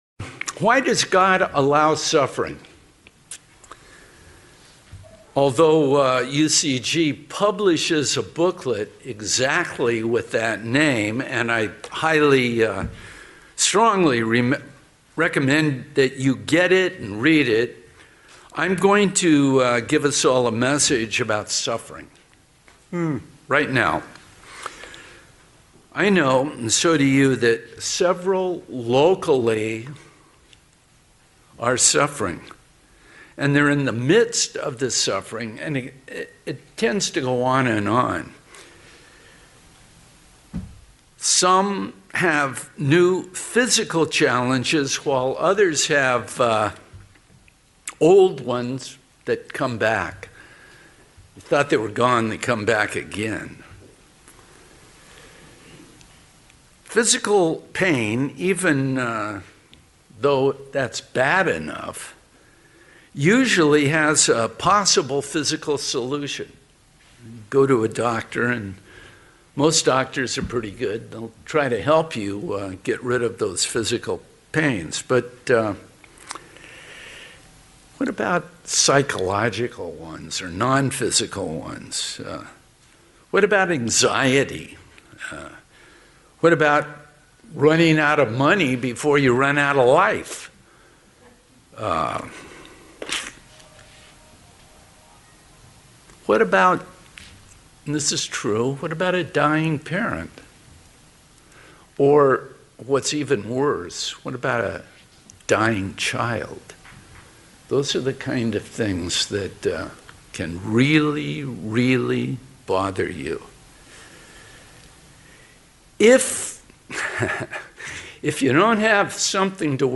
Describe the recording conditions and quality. Given in San Diego, CA Redlands, CA Las Vegas, NV